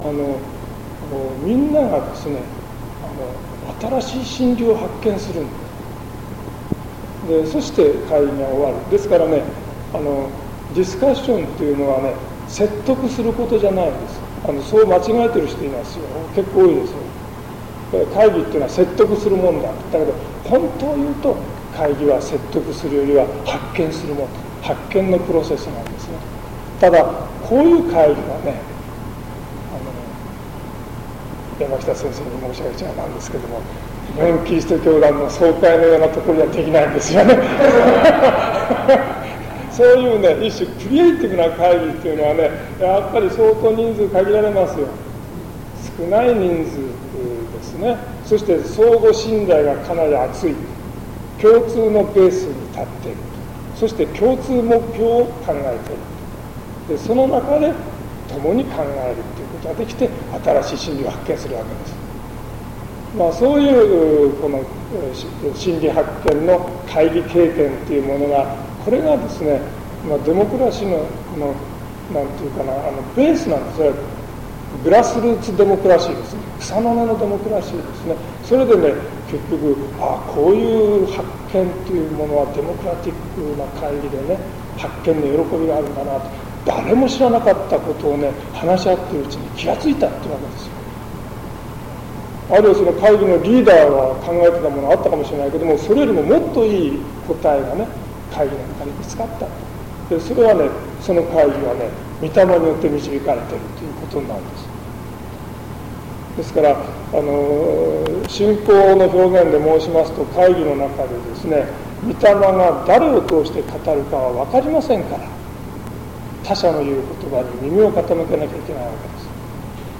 セミナー録音